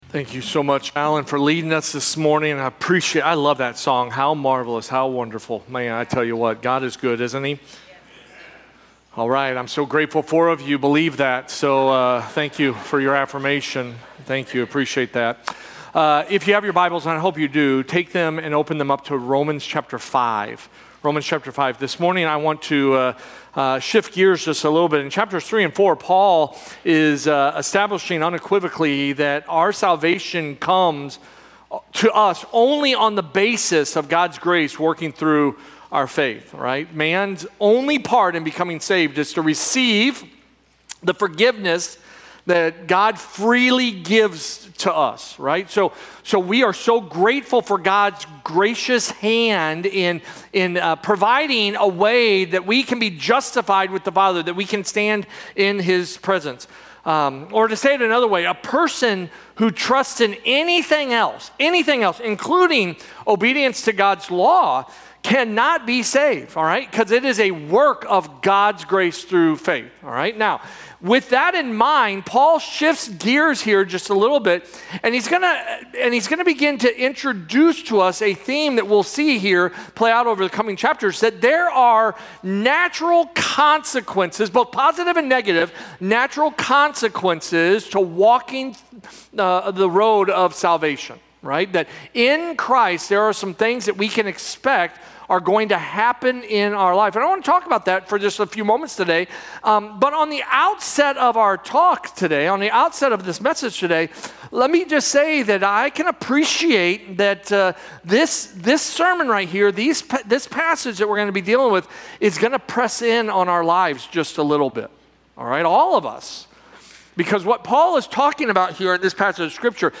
Sermons - First Baptist Church O'Fallon First Baptist Church O'Fallon